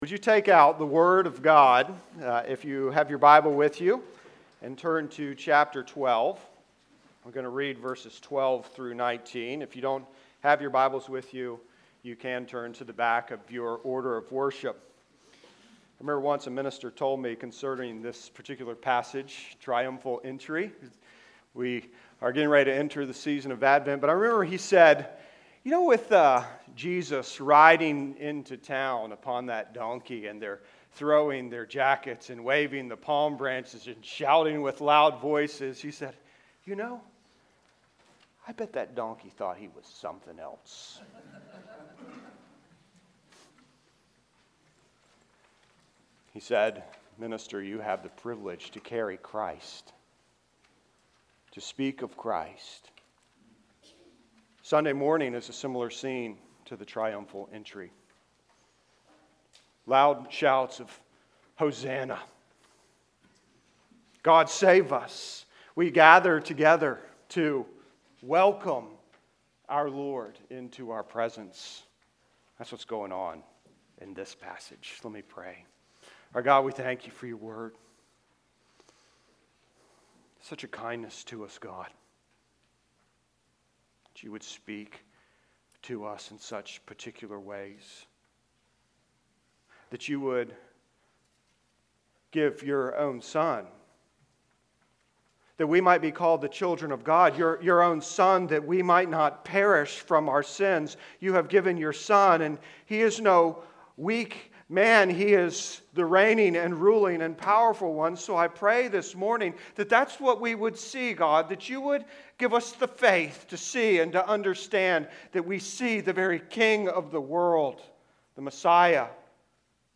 The Advent Of The King (John 12:12-19) Sermons And Lessons From All Saints Presbyterian Church podcast To give you the best possible experience, this site uses cookies.